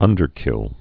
(ŭndər-kĭl)